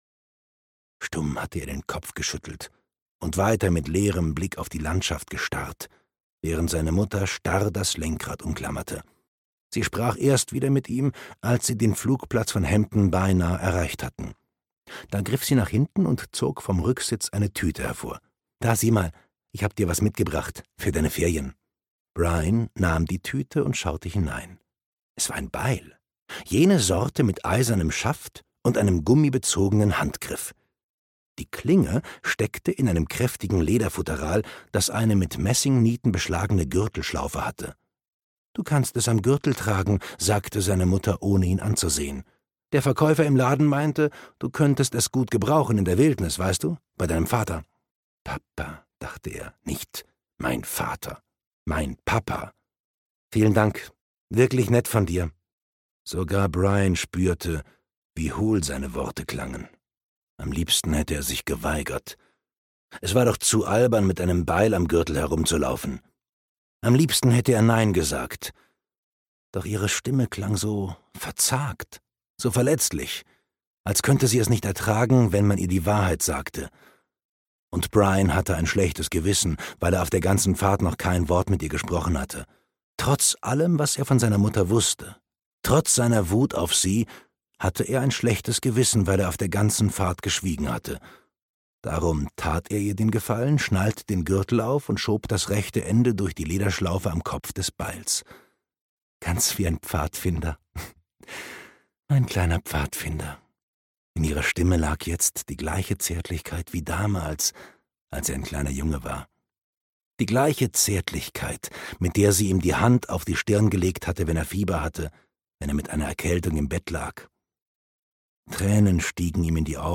Allein in der Wildnis - Gary Paulsen - Hörbuch